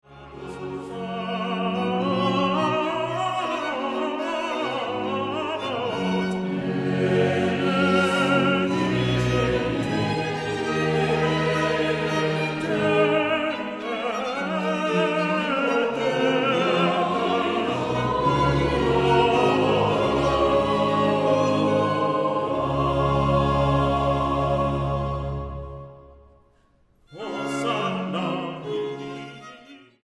Música Barroca Mexicana